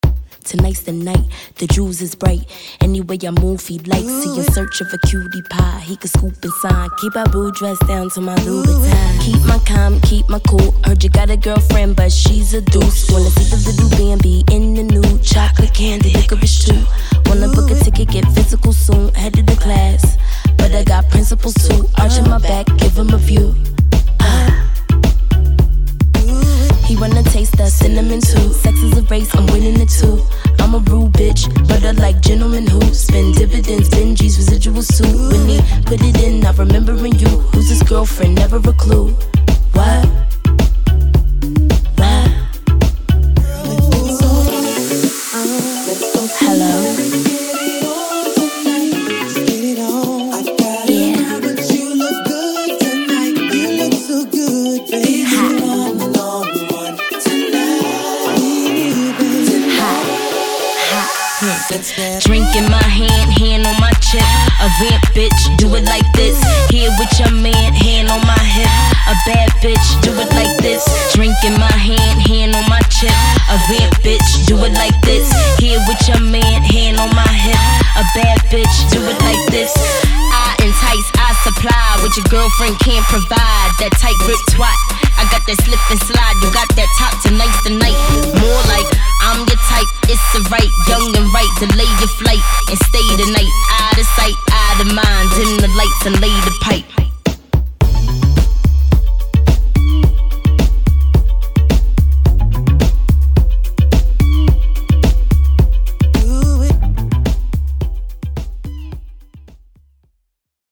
BPM54-432
Audio QualityPerfect (High Quality)